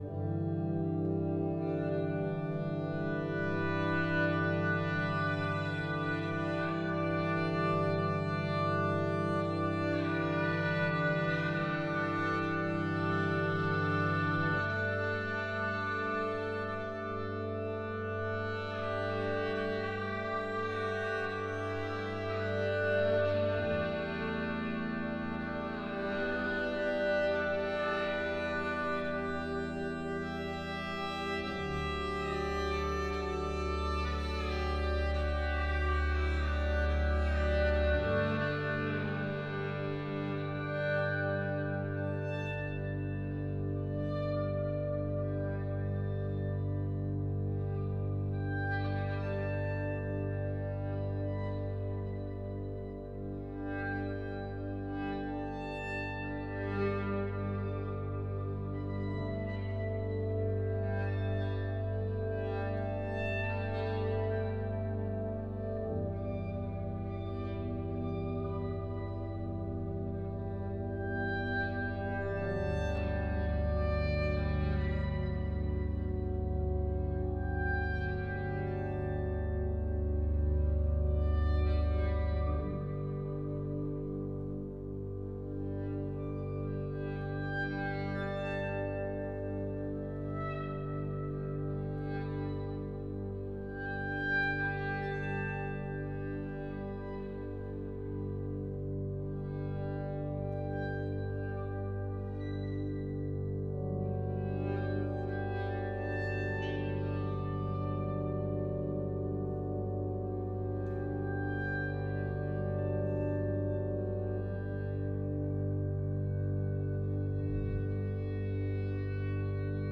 Live at St Mary’s Church, Felpham
Violin & Organ Concert at St Mary’s Felpham, 26th May 2025
A gentle, evolving piece held and moved the audience.
Here’s a section recorded at the rehearsal that afternoon: